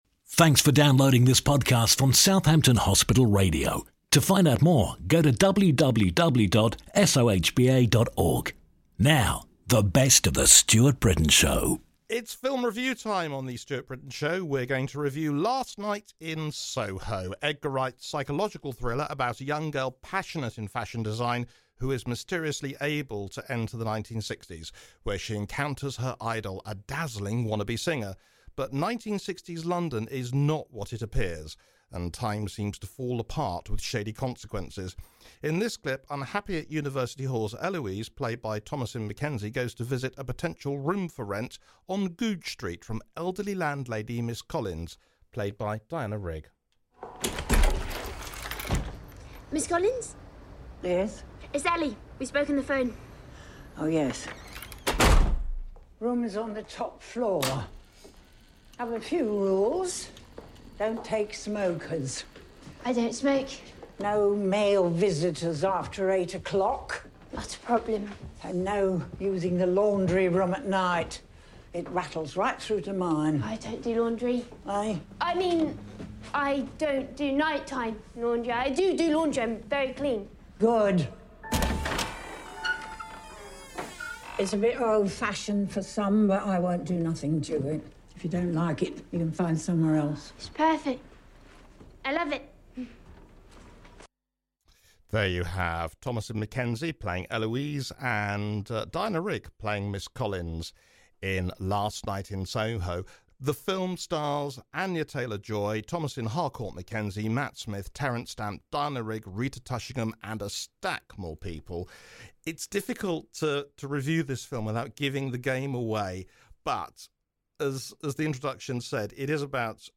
'Last Night In Soho' film clip courtesy Universal Pictures International (UK) Subscribe Next No Time To Die Top Episodes Mamma Mia!